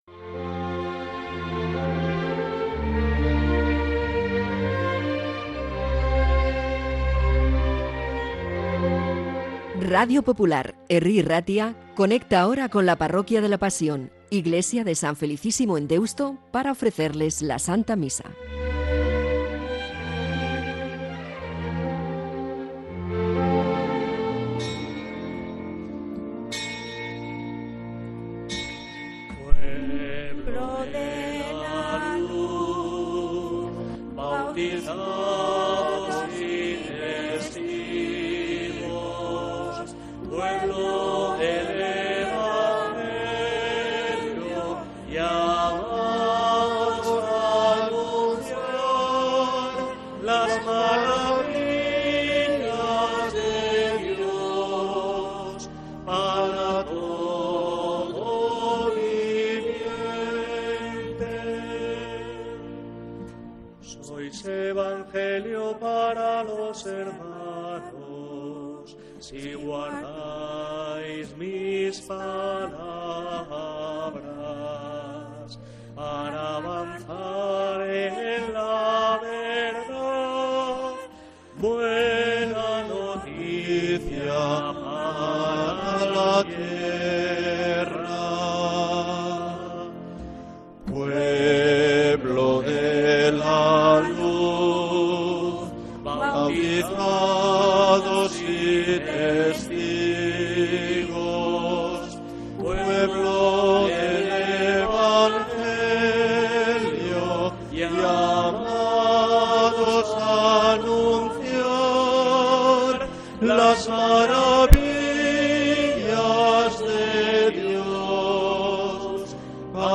Santa Misa desde San Felicísimo en Deusto, domingo 26 de octubre de 2025